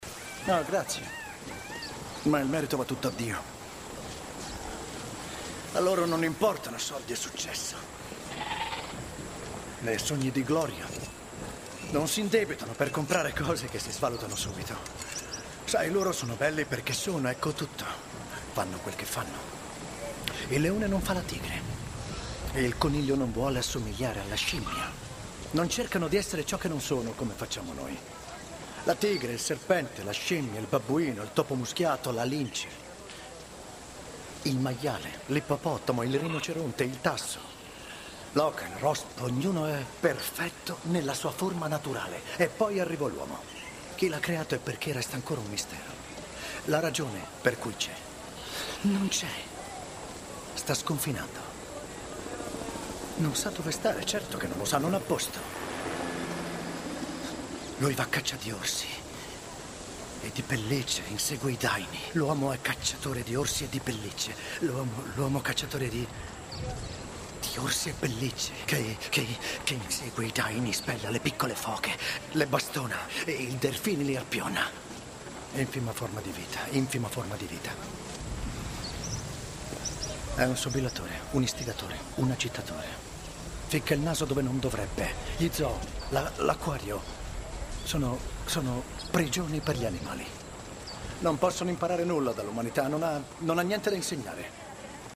nel film "Masked and Anonymous", in cui doppia Val Kilmer.